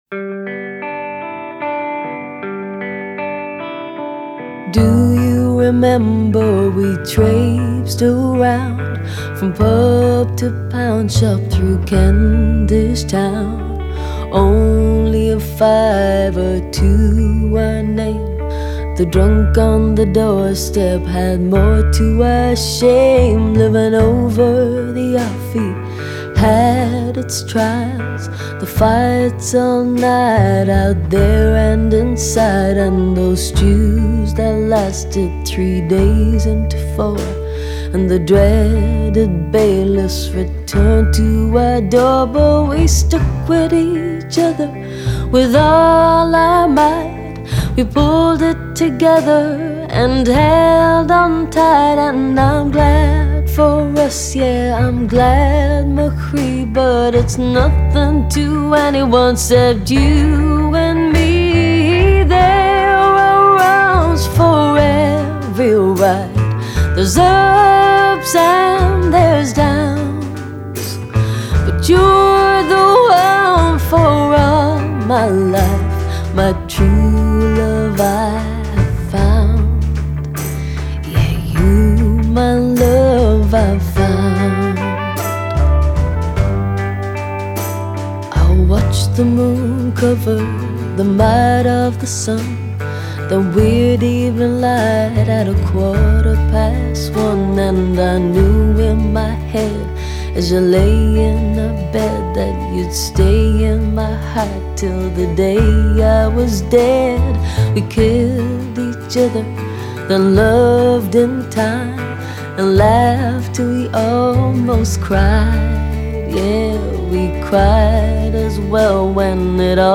Жанр: blues